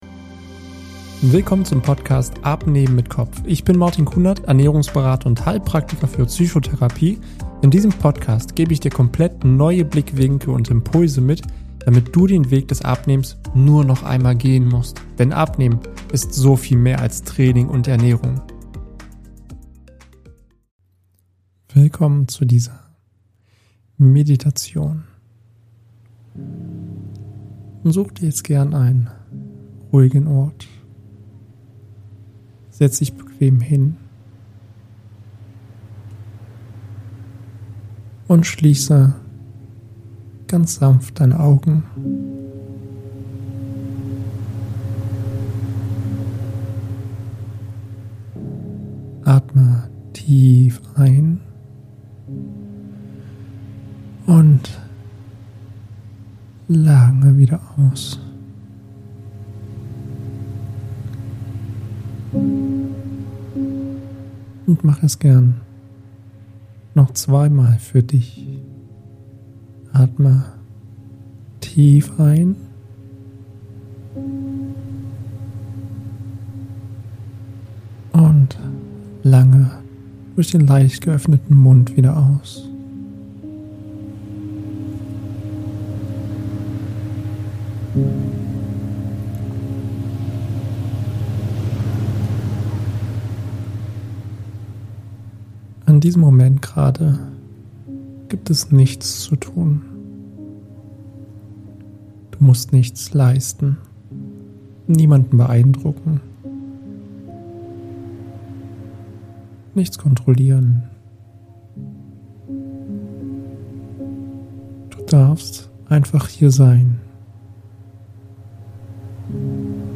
In diesem zweiten Teil erwartet dich eine tiefgehende, geführte Meditation, die dich dabei unterstützt, das Gefühl „Ich bin nicht genug“ emotional und körperlich zu lösen.